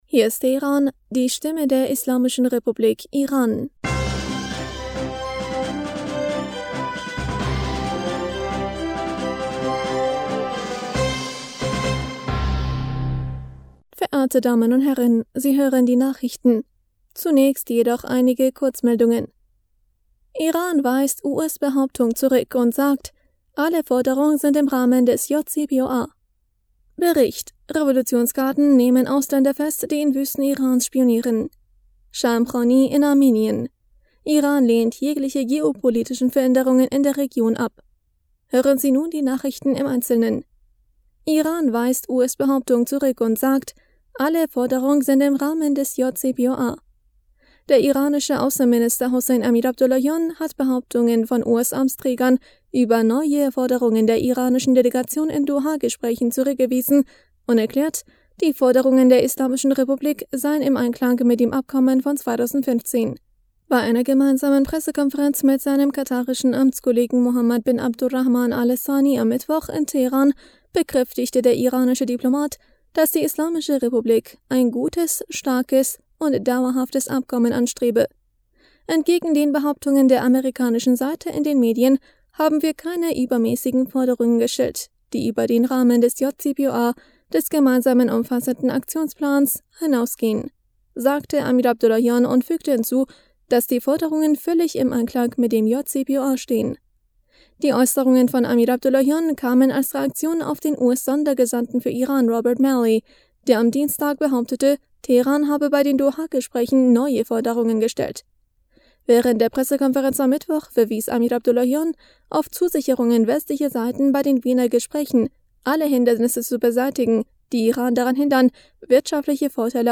Nachrichten vom 7. Juli 2022